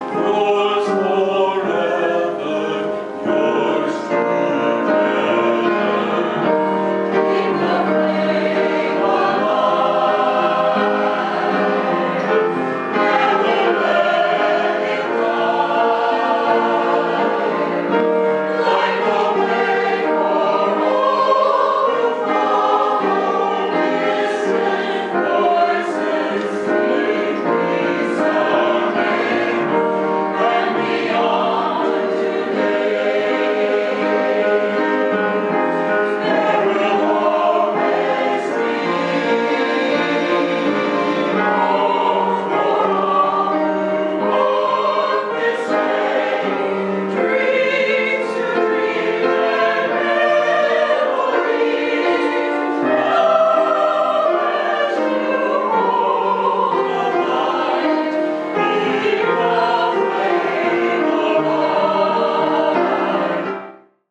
Picture from the Flower Communion on June 3:
Here is an MP3 of the PH Chorale at the program: